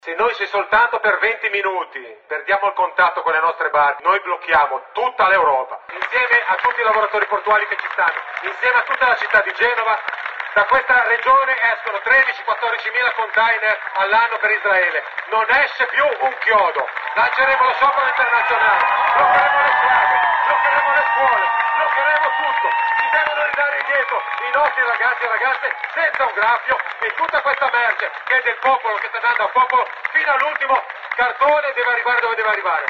In 40mila a Genova per accompagnare la partenza della Global Sumud Flottilla. Sul palco i lavoratori portuali sono pronti a bloccare tutto a difesa degli attivisti.